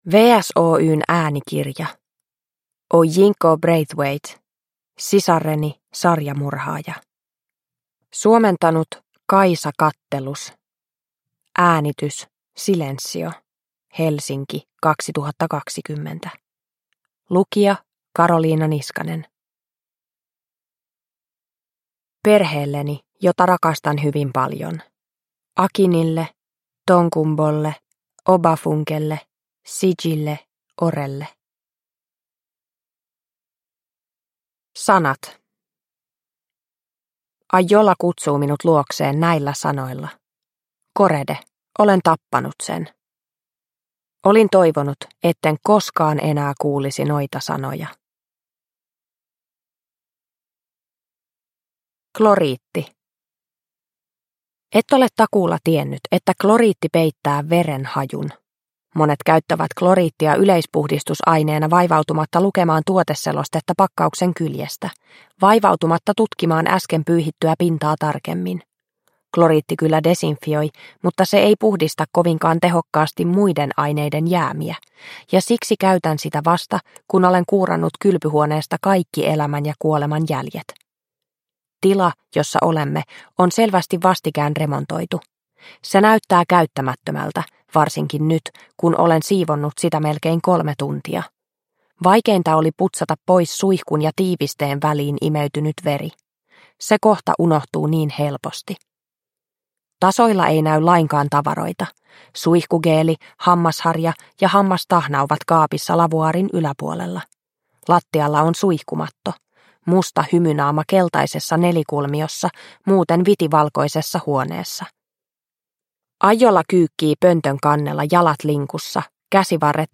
Sisareni, sarjamurhaaja – Ljudbok – Laddas ner